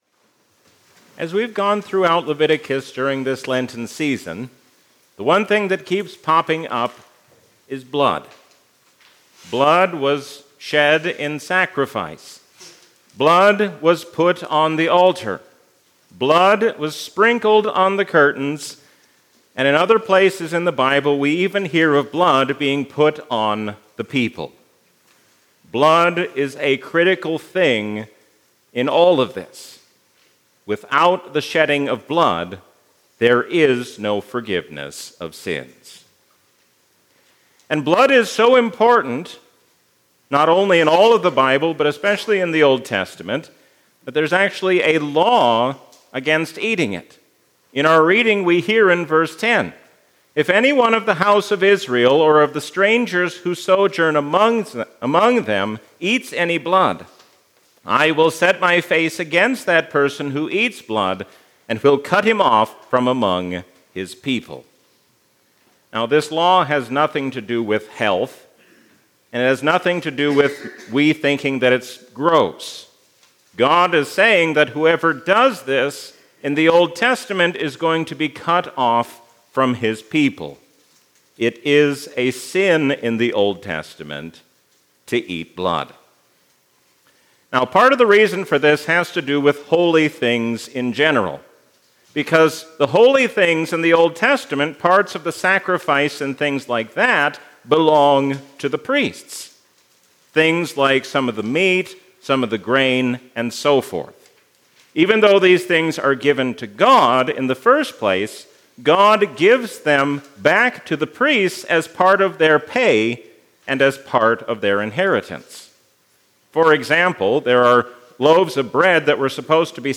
A sermon from the season "Lent 2021." Jesus is our Passover lamb, our substitute so that we may live.